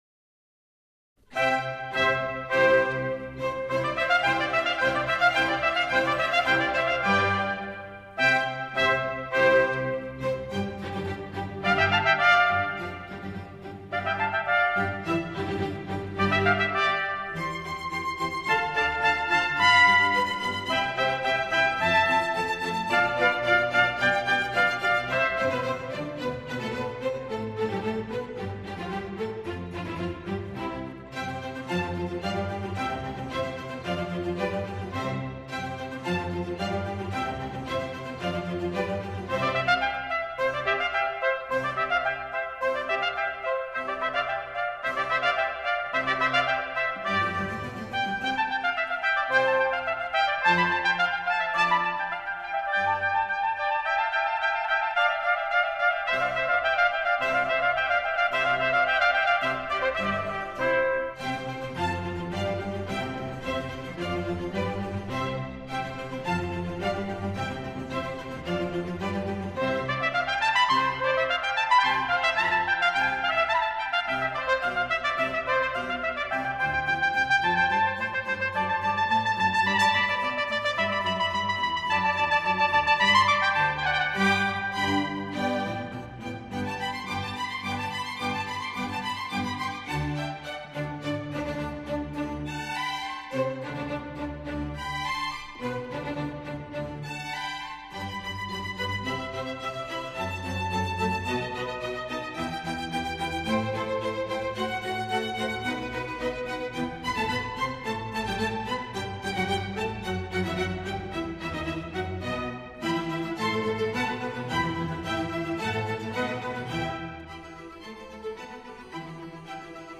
小号
Concerto for 2 Trumpets and Strings in C-Major